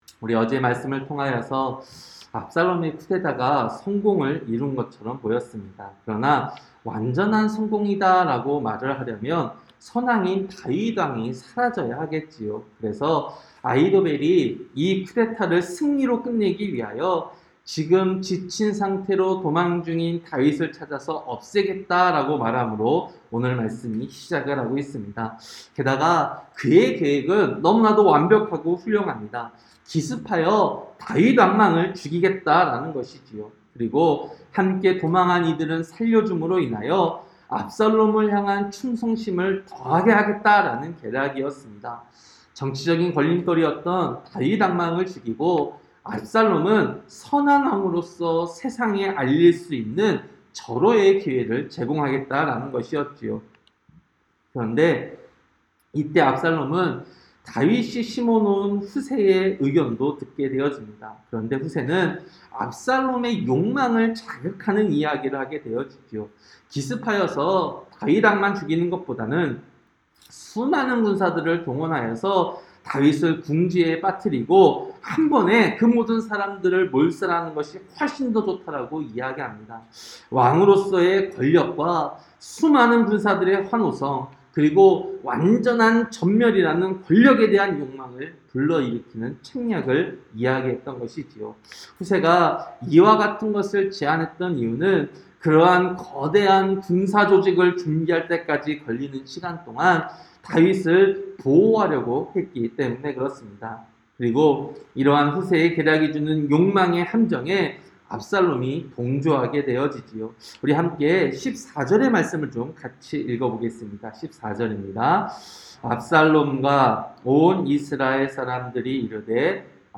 새벽설교-사무엘하 17장